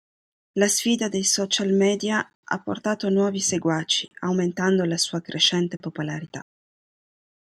Read more Verb Intj Frequency A1 Pronounced as (IPA) /ˈa/ Etymology See the etymology of the corresponding lemma form.